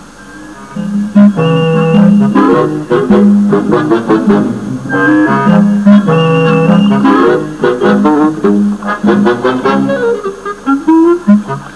Petit intermede musical (